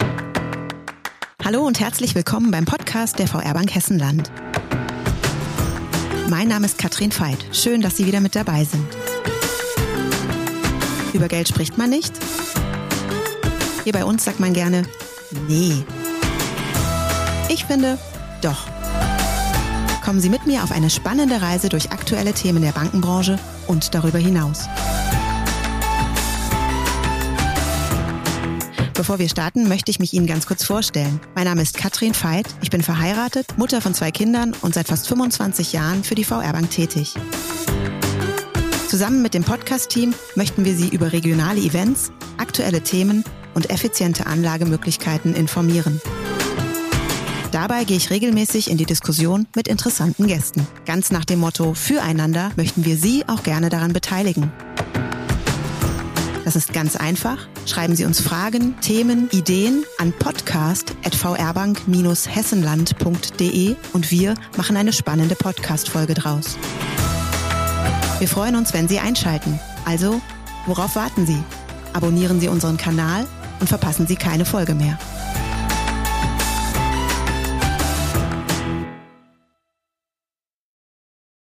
Regional, kompetent und mit spannenden Interviewgästen